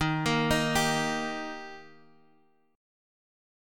Eb chord